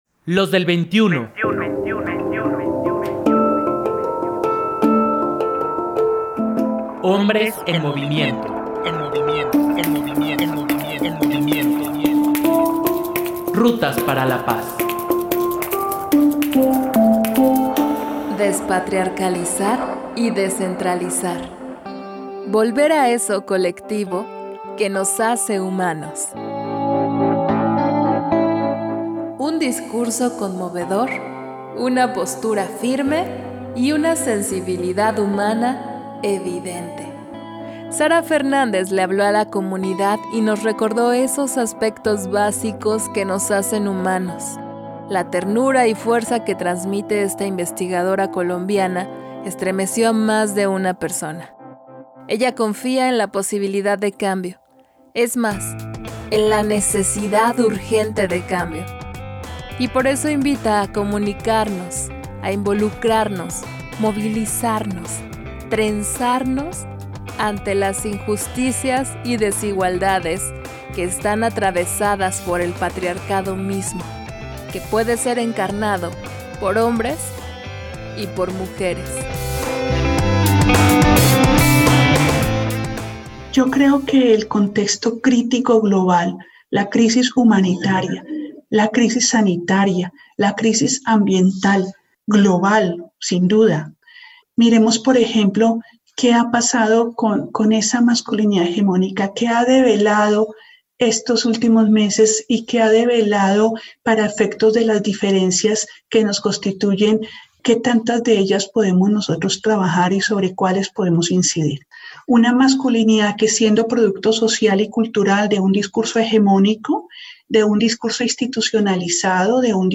Un discurso conmovedor, una postura firme y una sensibilidad humana evidente…